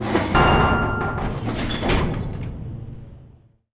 Trade Thumb Confirm Sound Effect
Download a high-quality trade thumb confirm sound effect.
trade-thumb-confirm.mp3